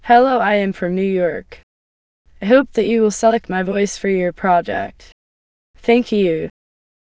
samples/VCTK_p297.wav · voices/VCTK_American_English_Females at main